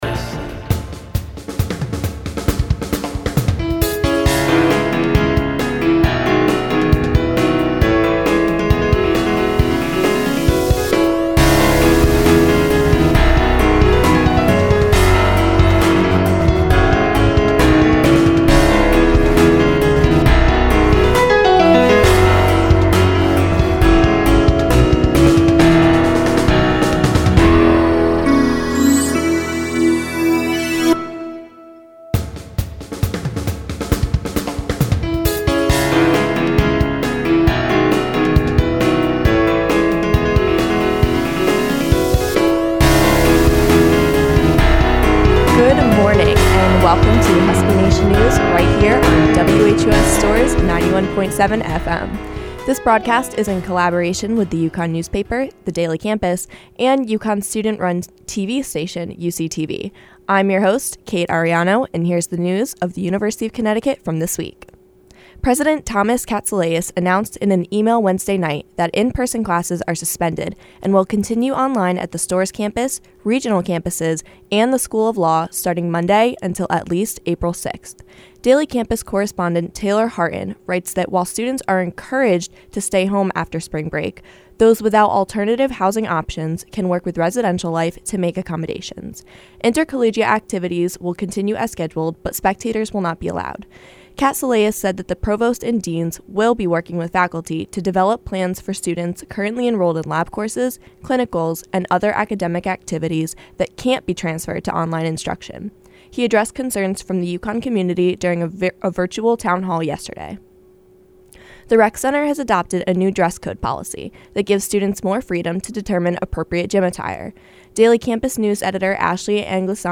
This podcast is a digital archive of the WHUS News Team broadcast which airs on 91.7 FM once a week.